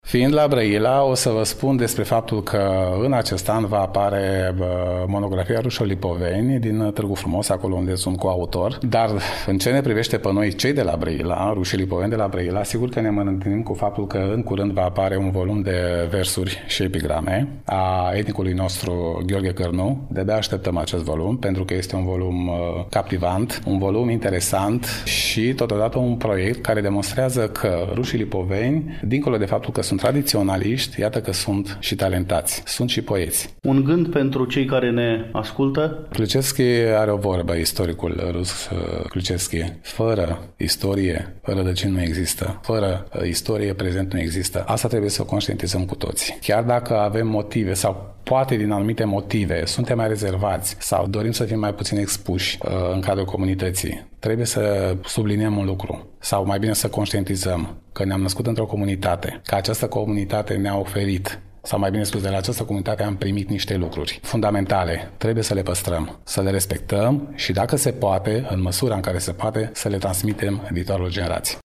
În emisiunea de astăzi poposim acasă la rușii lipoveni din Brăila, mai exact în incinta sediului cultural al Comunității din cartierul Pisc, de pe strada Alexandru Davila, Numărul 13.